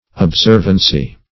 Observancy \Ob*serv"an*cy\, n.